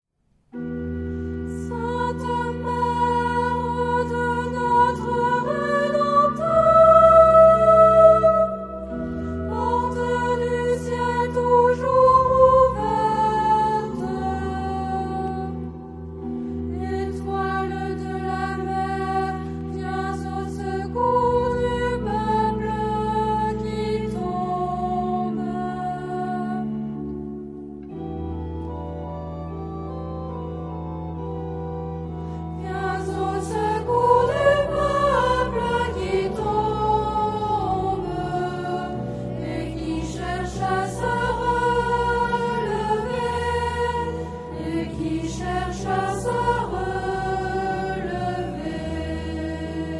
Genre-Style-Form: Antiphon ; Sacred
Mood of the piece: solemn
Type of Choir: unisson
Instruments: Organ (1)
Tonality: E minor